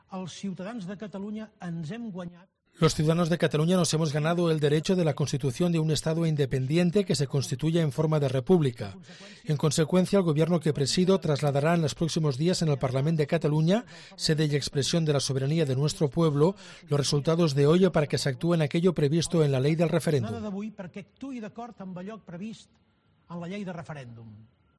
En una declaración institucional desde el Palau de la Generalitat, Puigdemont ha destacado que este domingo Cataluña ha "conseguido celebrar el referéndum que el Estado se ha esforzado tanto en impedir" y ha hecho una "apelación directa" a la Unión Europea a que, ante la "intransigencia y la represión" del Estado, asuma que la cuestión catalana "ya no es un asunto interno" de España sino un "asunto de interés europeo".